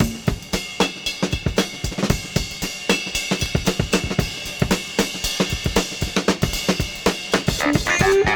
• 115 Bpm HQ Drum Loop Sample A# Key.wav
Free breakbeat - kick tuned to the A# note. Loudest frequency: 2245Hz
115-bpm-hq-drum-loop-sample-a-sharp-key-4cH.wav